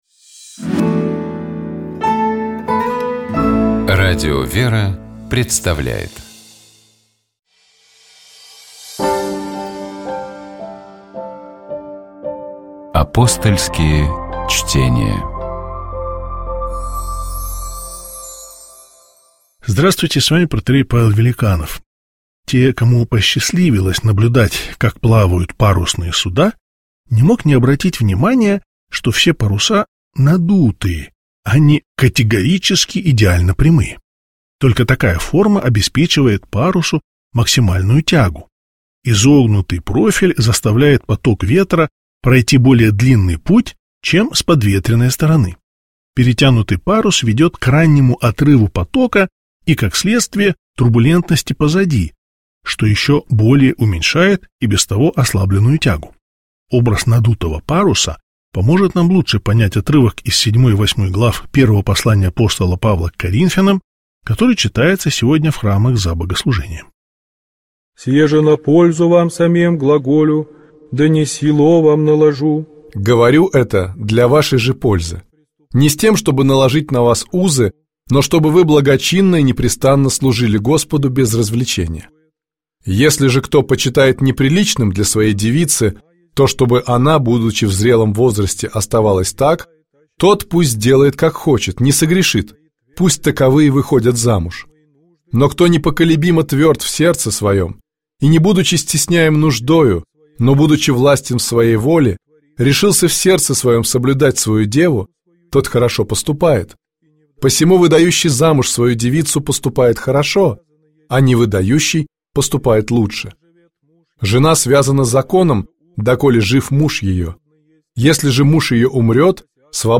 Только что прозвучал фрагмент из этой документальной киноленты.